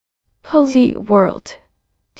Another issue I found is that some simple words is spoken weirdly.
I'm using the mini model.
the hello is wrong.